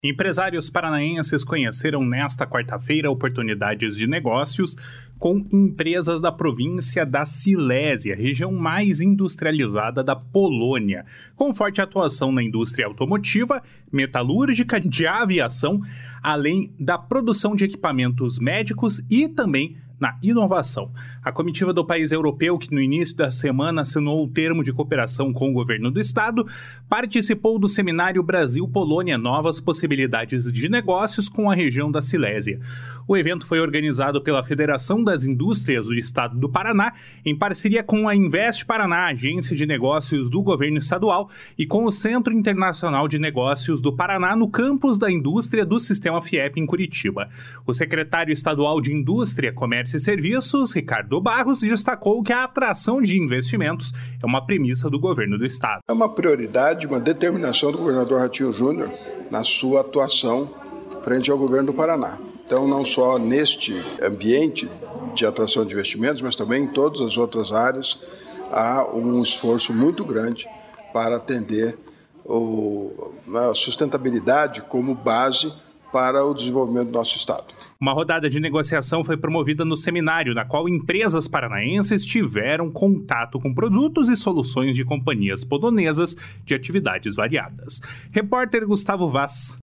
// SONORA RICARDO BARROS //